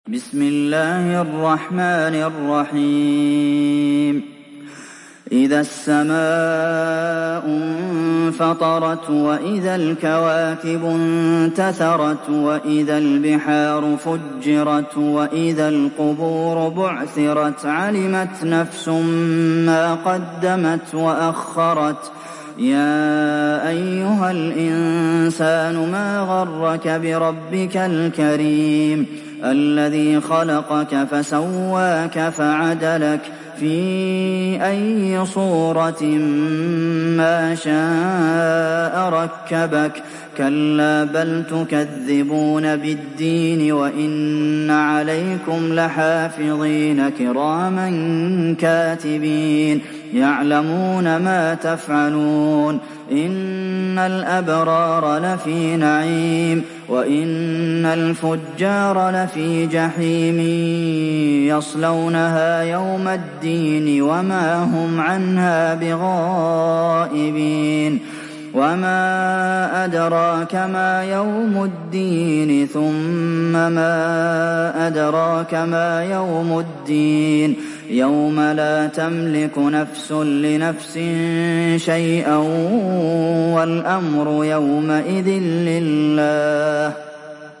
Surat Al Infitar Download mp3 Abdulmohsen Al Qasim Riwayat Hafs dari Asim, Download Quran dan mendengarkan mp3 tautan langsung penuh